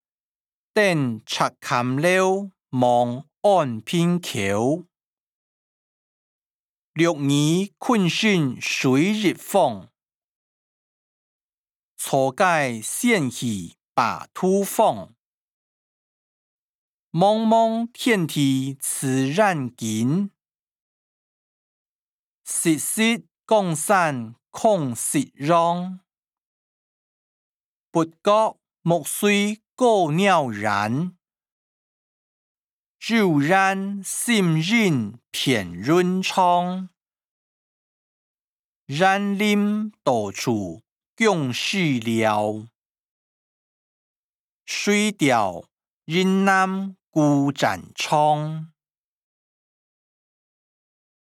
古典詩-登赤嵌樓望安平口音檔(海陸腔)